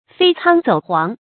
飛蒼走黃 注音： ㄈㄟ ㄘㄤ ㄗㄡˇ ㄏㄨㄤˊ 讀音讀法： 意思解釋： 指打獵。